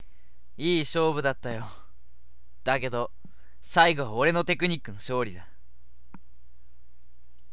「頭文字D」風に勝利のキメ台詞